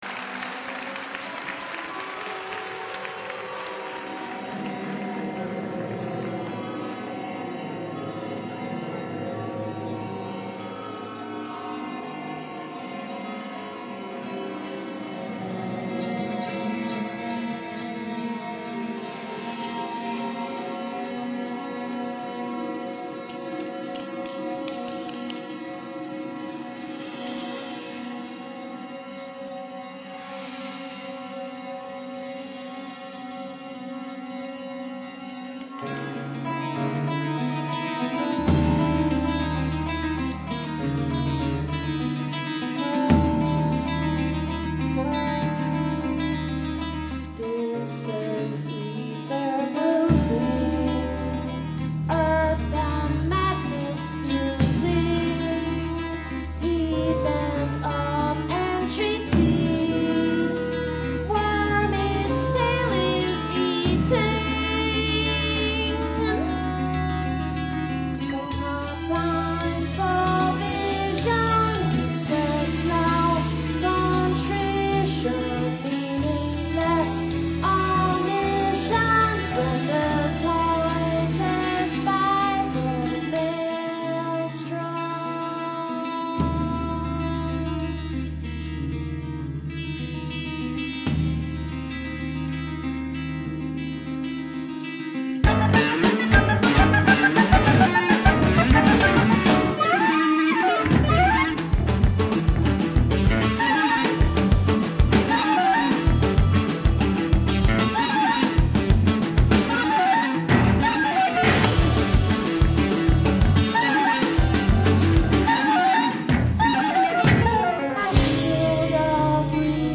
guitars
saxes and clarinet
bass
voice and keys
drums
live album